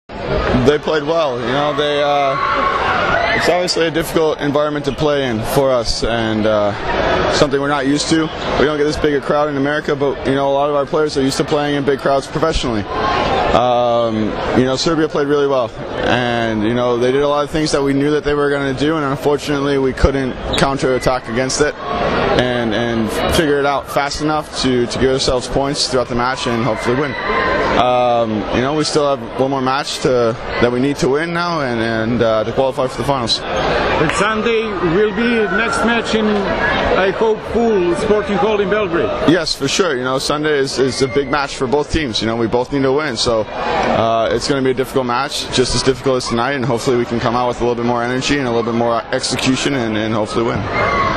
IZJAVA METJUA ANDERSONA